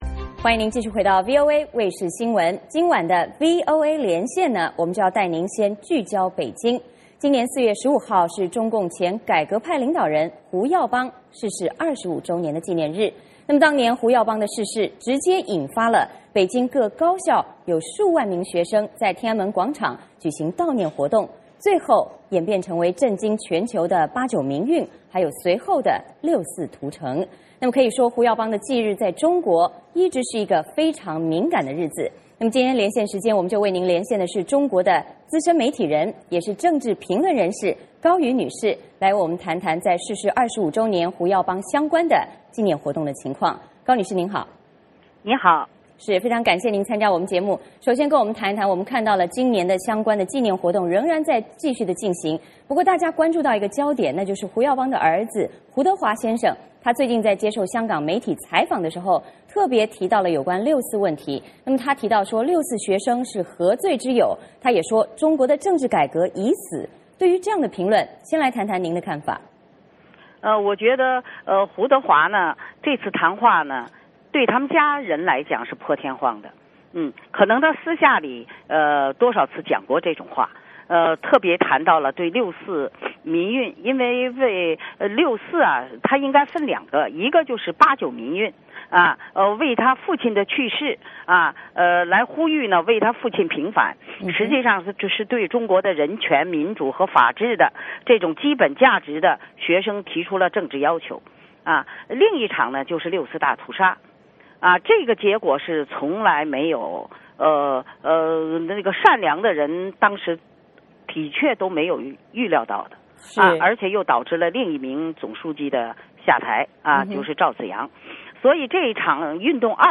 最近媒体报道，前总书记胡锦涛低调访问了胡耀邦的故居，有人认为，这是胡锦涛展现对习近平打贪腐的支持。胡耀邦与打贪腐为什么能联结在一起?这是在震慑江泽民?我们连线中国资深媒体人、政治评论人士高瑜女士谈谈有关胡耀邦逝世25周年的相关情况。